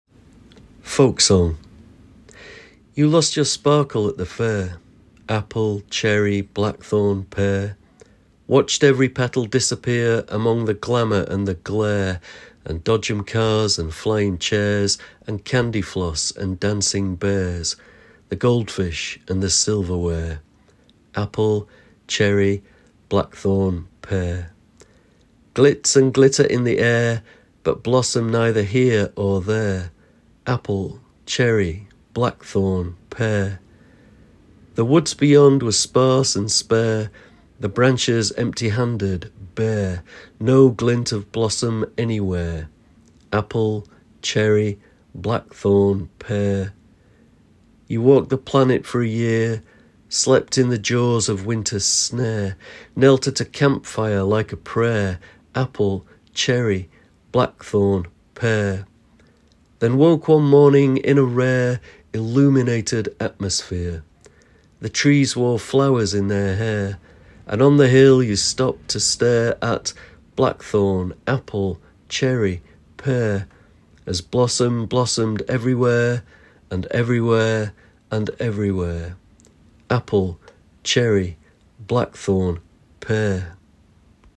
Poet Laureate Simon Armitage reads his poem Folk Song. Folk Song is part of Blossomise, a collection of poetry and music launched in 2024 to celebrate the arrival of spring.